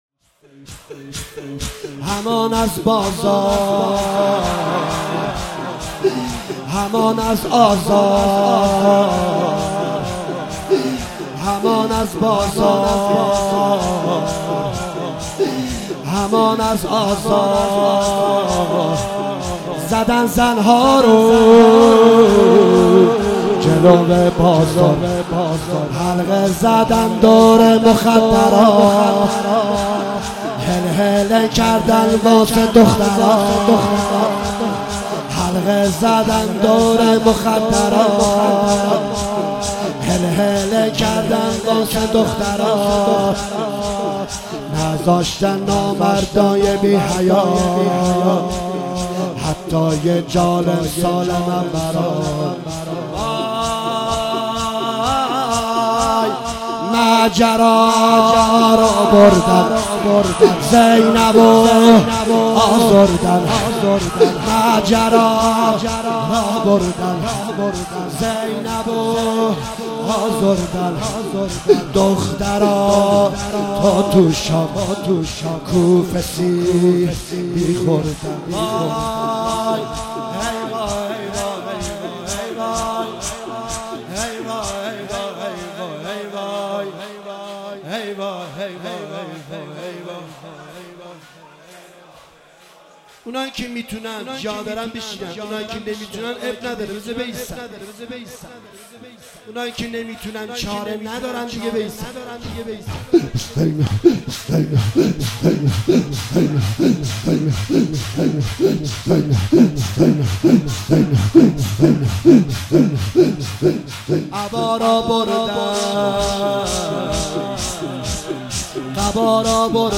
شب چهارم محرم97/هیئت مکتب الحسین(ع)
شور